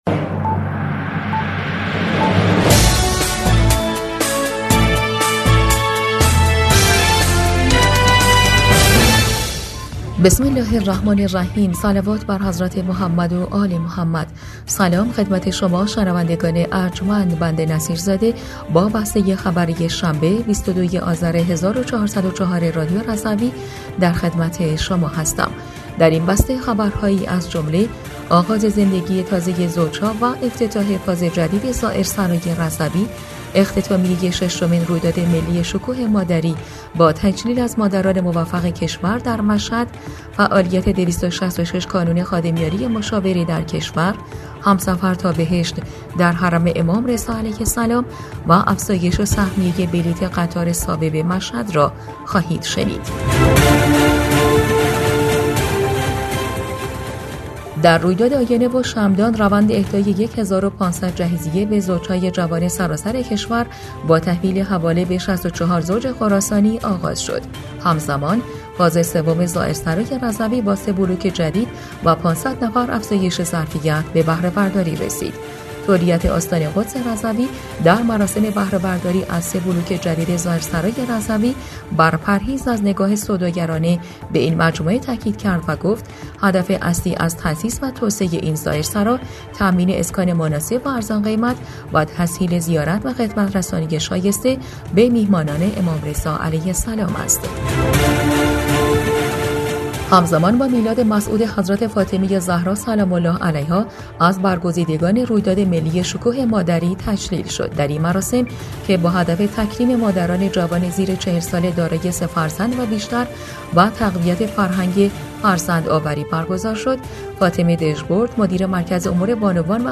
بسته خبری ۲۲ آذر ۱۴۰۴ رادیو رضوی؛